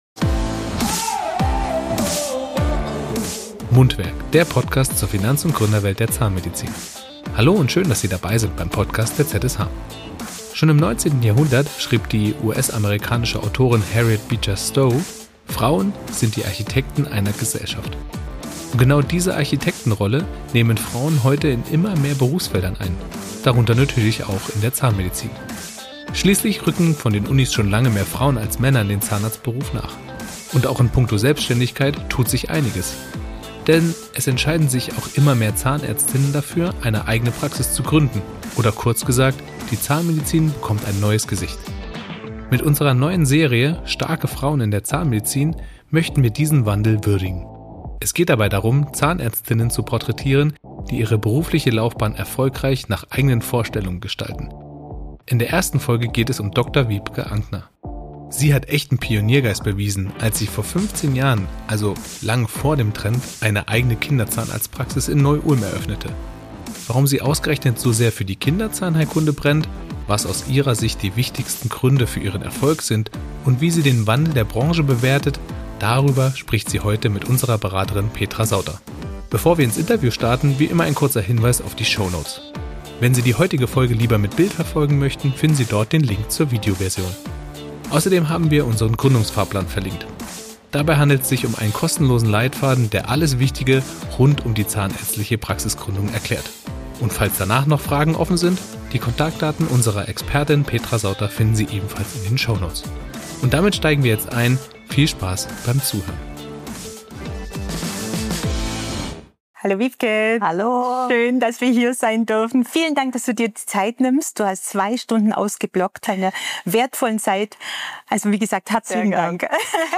im Interview ~ Mundwerk – Der Podcast zur Finanz- und Gründerwelt der Zahnmedizin Podcast